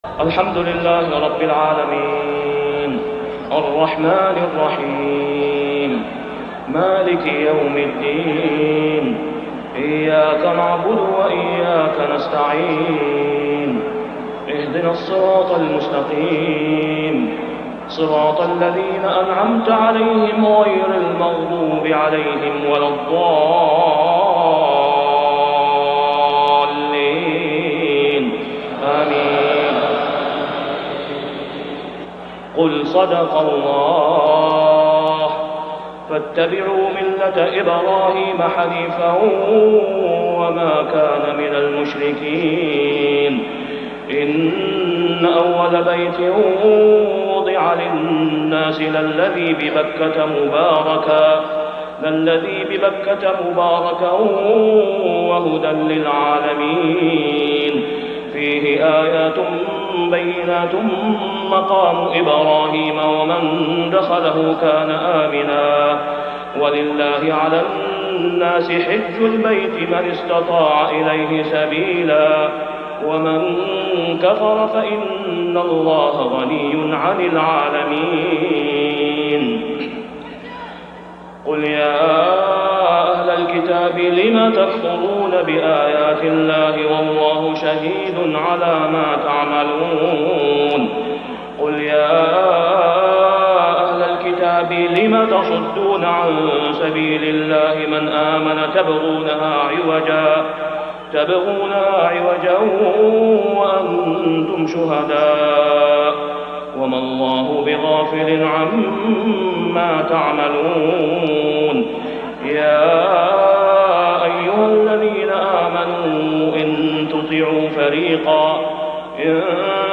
صلاة العشاء موسم الحج ( العام غير معروف ) | سورة آل عمران 94-108 > 1424 🕋 > الفروض - تلاوات الحرمين